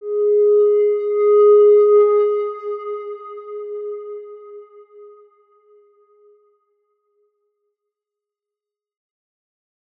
X_Windwistle-G#3-pp.wav